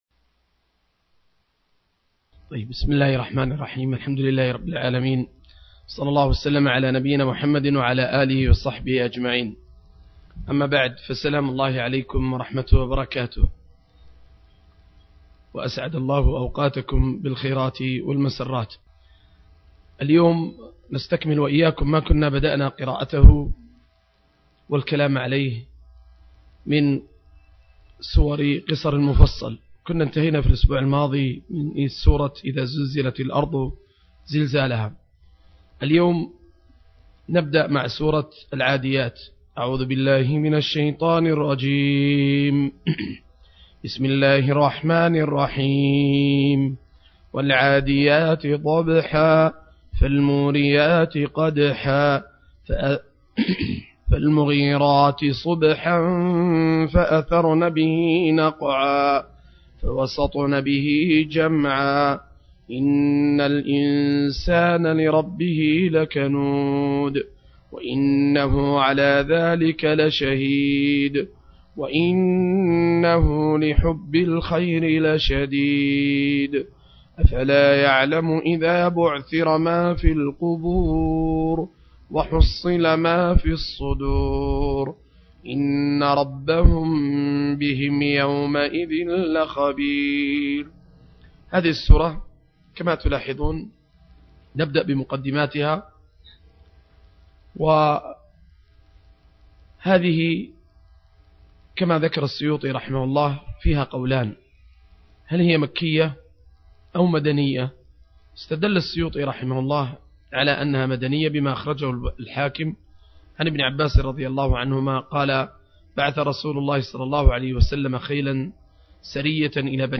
درس